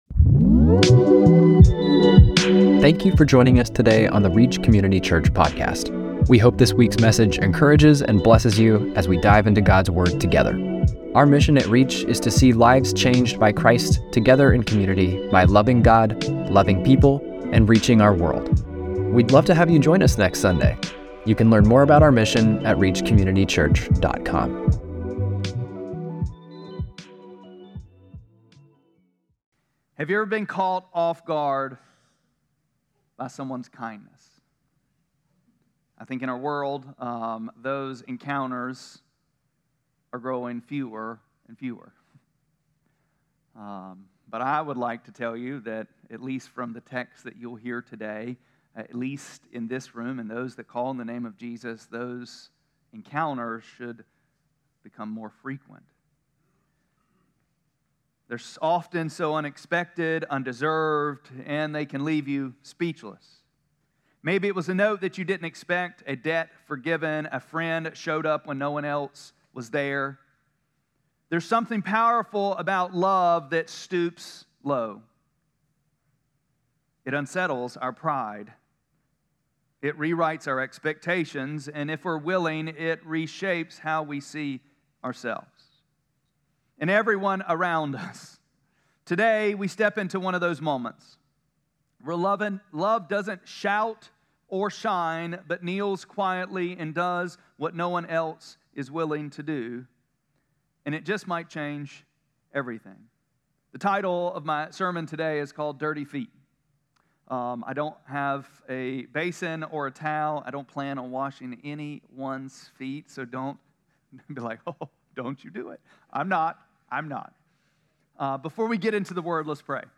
5-25-25-Sermon.mp3